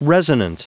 Prononciation du mot resonant en anglais (fichier audio)
Prononciation du mot : resonant